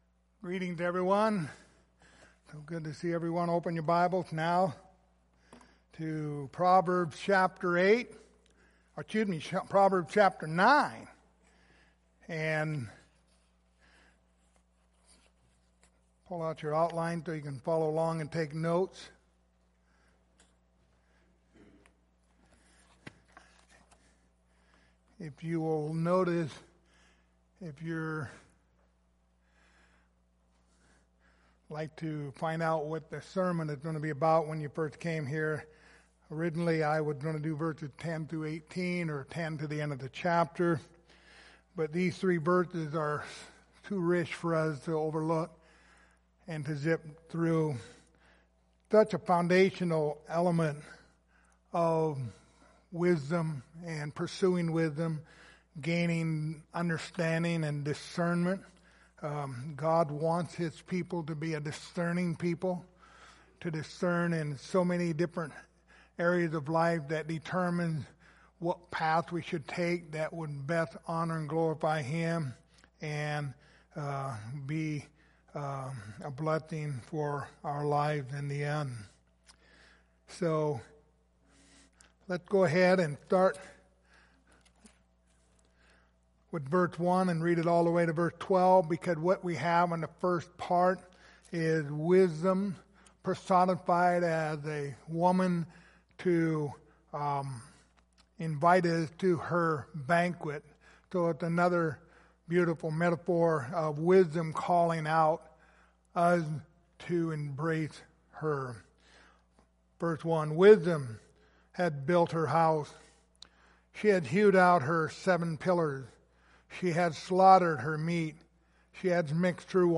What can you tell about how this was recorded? The Book of Proverbs Passage: Proverbs 9:10-12 Service Type: Sunday Morning Topics